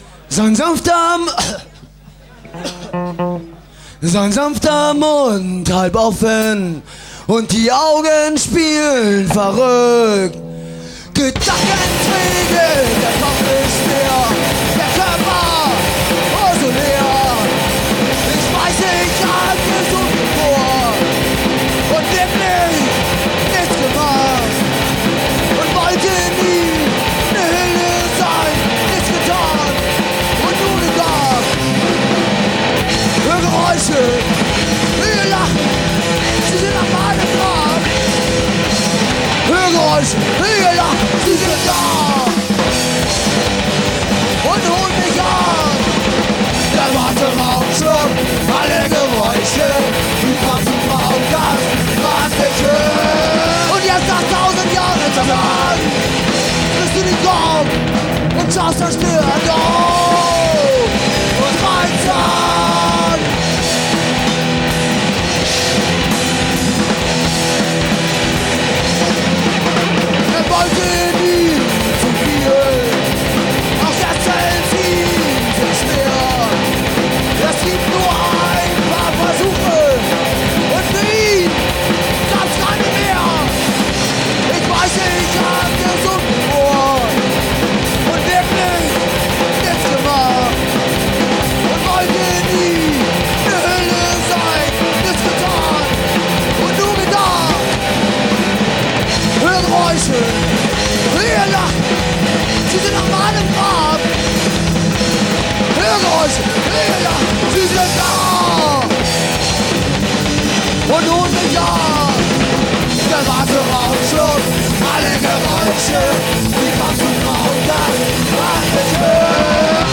Kurze Reunion für 2 Konzerte